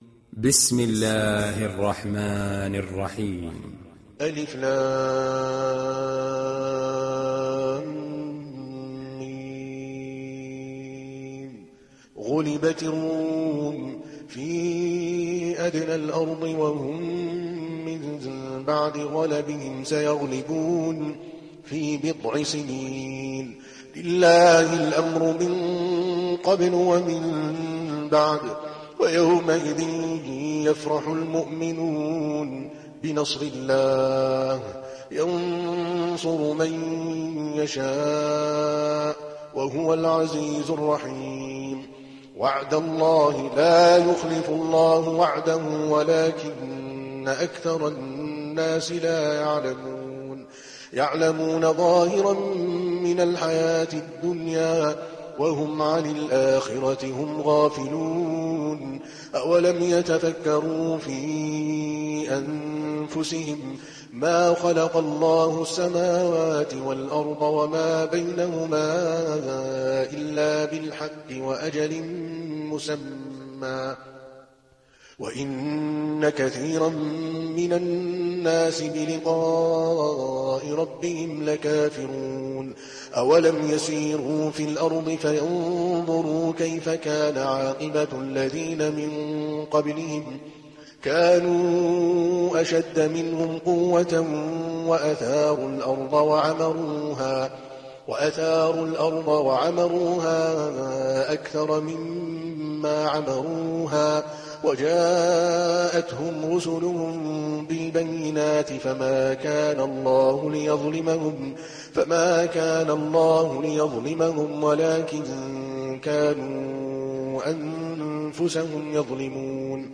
Rum Suresi İndir mp3 Adel Al Kalbani Riwayat Hafs an Asim, Kurani indirin ve mp3 tam doğrudan bağlantılar dinle